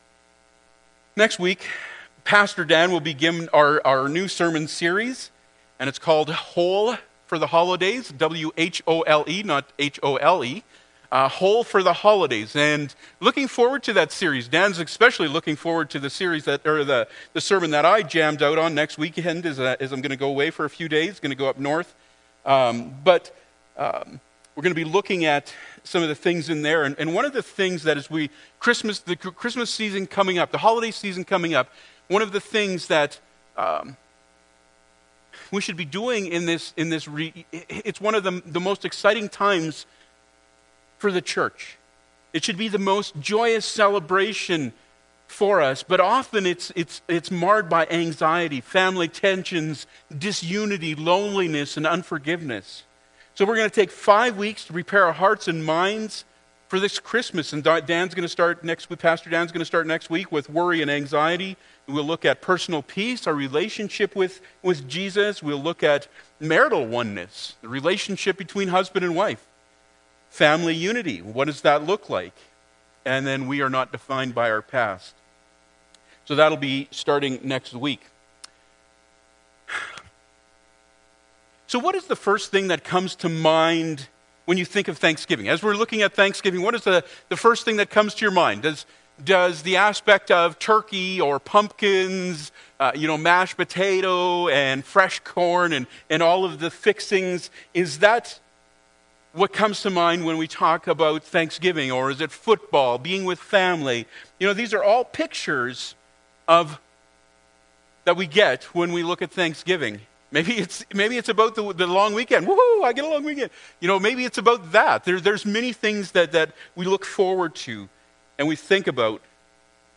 Ps 50:14-23 Service Type: Sunday Morning Bible Text